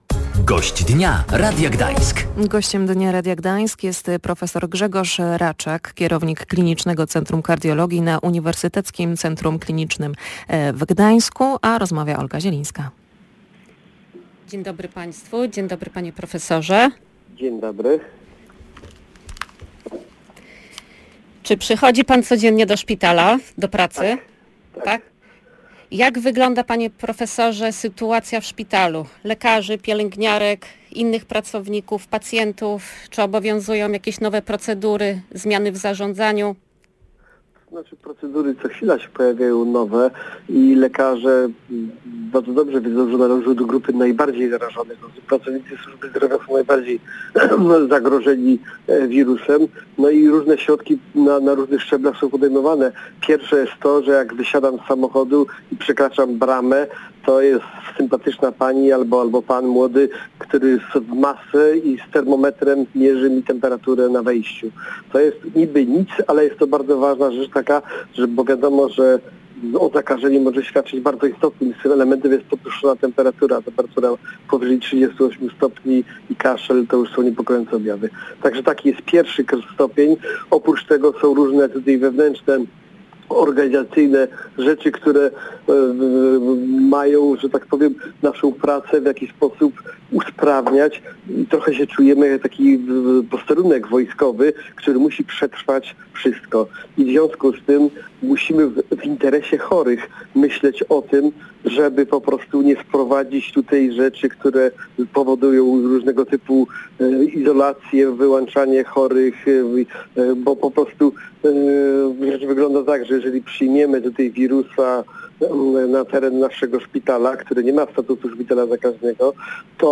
Gościem Dnia Radia Gdańsk